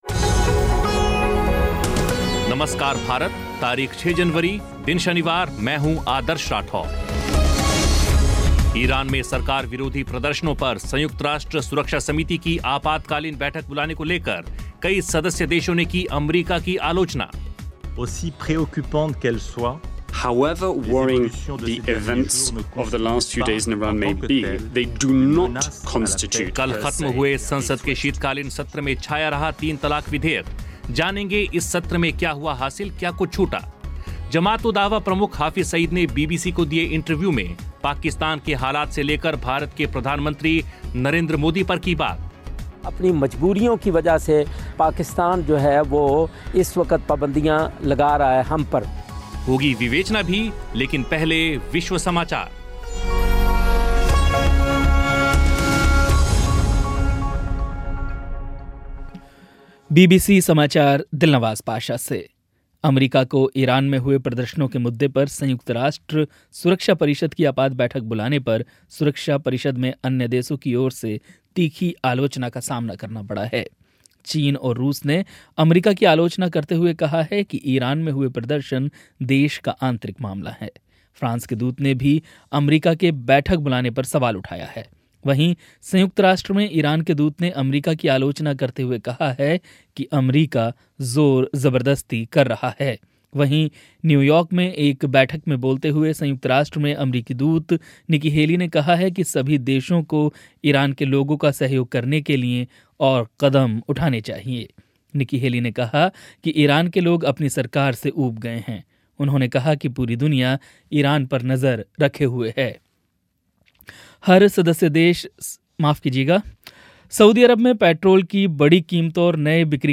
जमात उद दावा प्रमुख हाफ़िज़ सईद ने बीबीसी को दिए इंटरव्यू में पाकिस्तान के हालात से लेकर भारत के प्रधानमंत्री नरेंद्र मोदी पर की बात